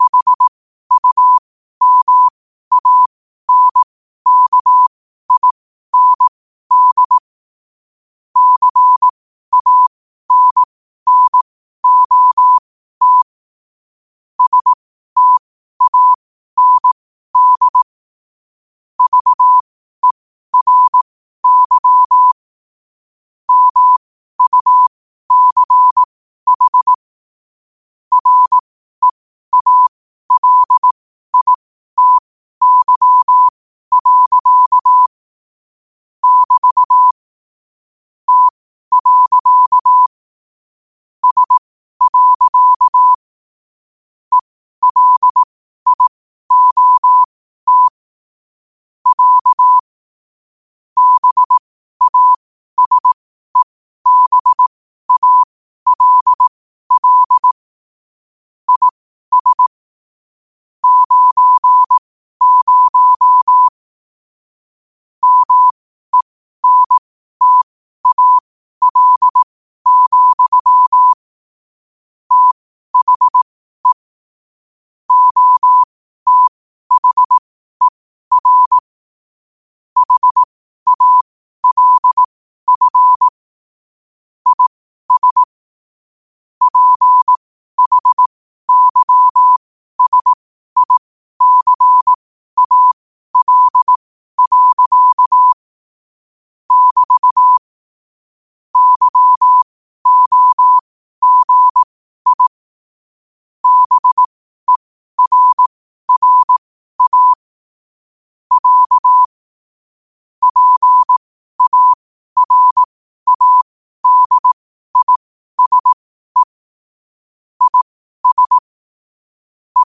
Quotes for Thu, 14 Aug 2025 in Morse Code at 12 words per minute.
Play Rate Listened List Bookmark Get this podcast via API From The Podcast Podcasts of famous quotes in morse code.